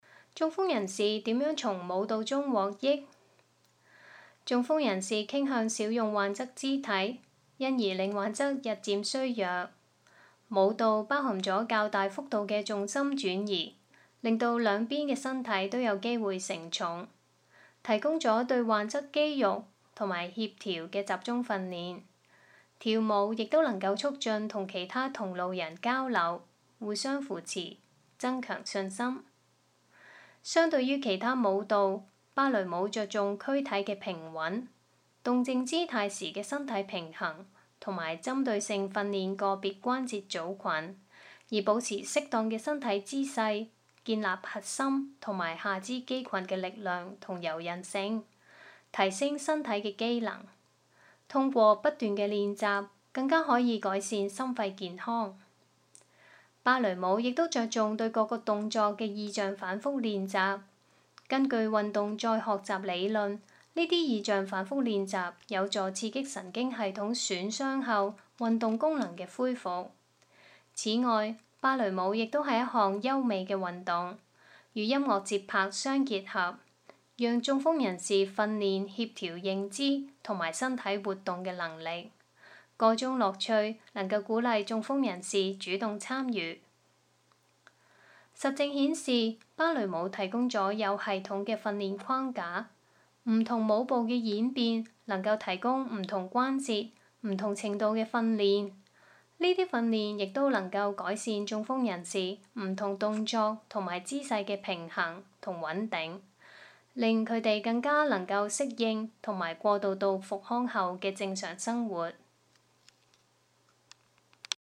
聲音導航